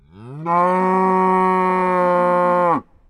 cow.ogg